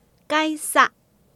臺灣客語拼音學習網-進階學習課程-饒平腔-第五課